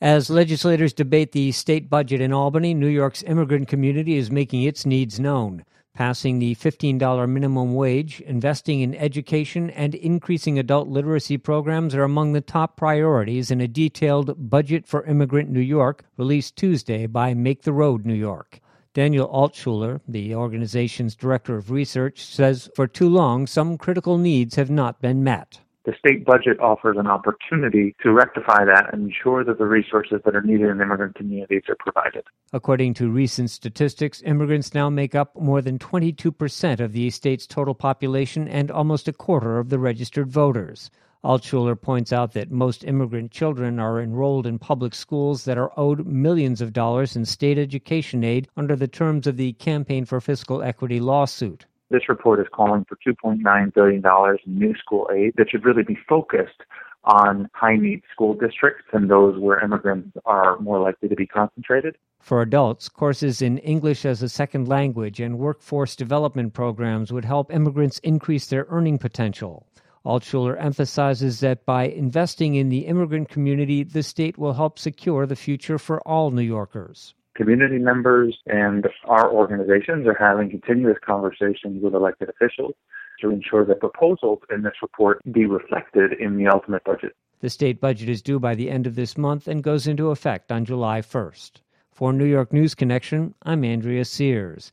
WGXC Evening News